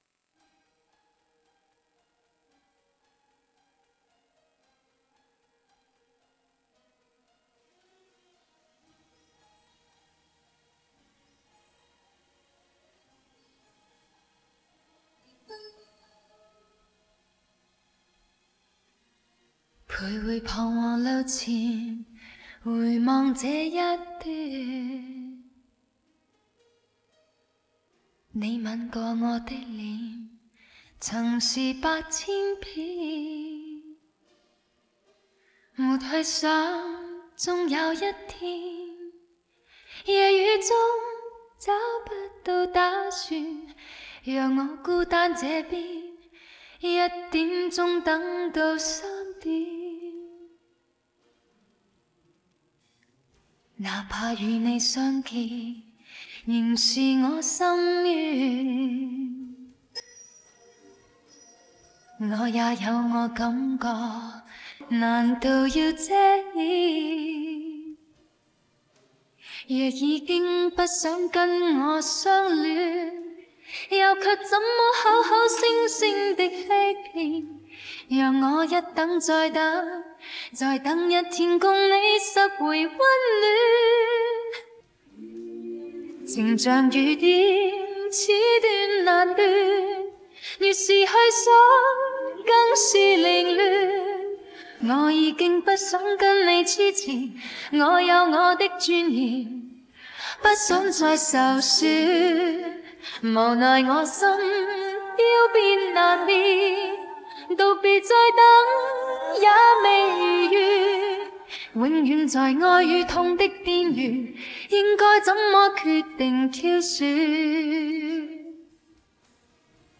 Rename 4_4_(Vocals).wav to 4.wav